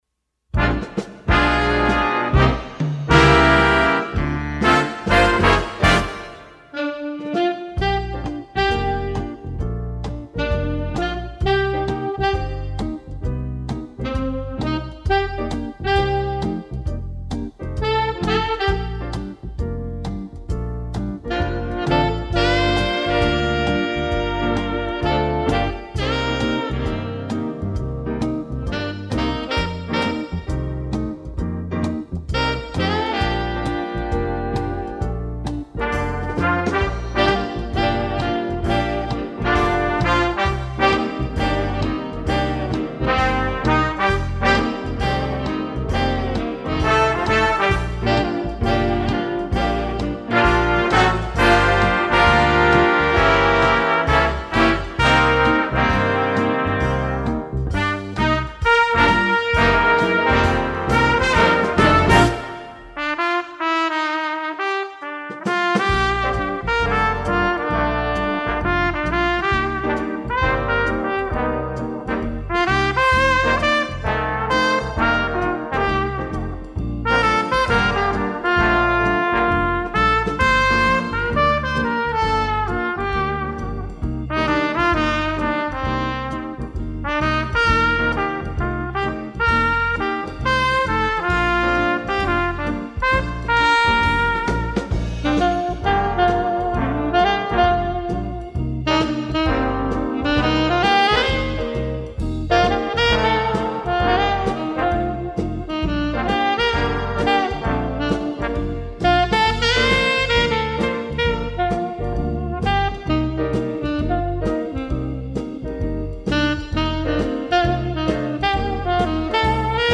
Jazz Band